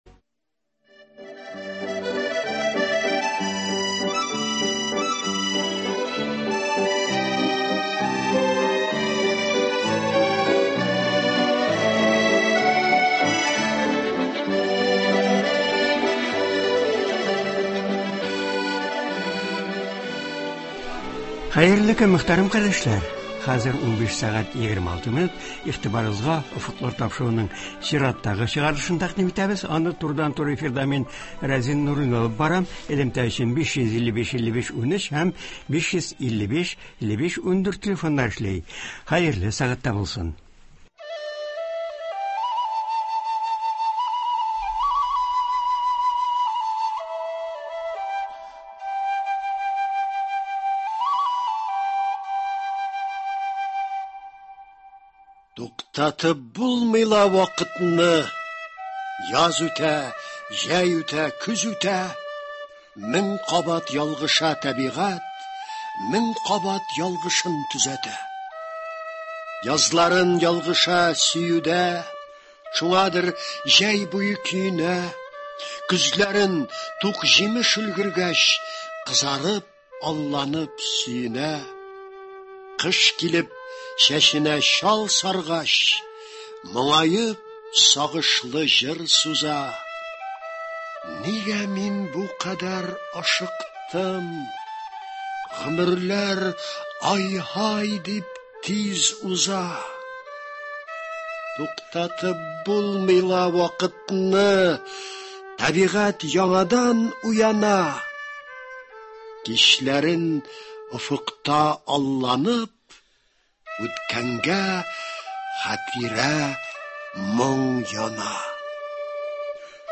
тыңлаучылар сорауларына җавап бирә.